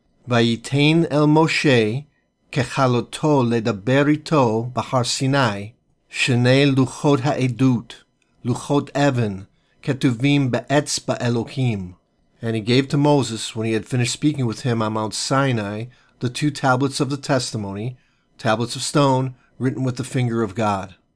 Exodus 31:18 Hebrew reading: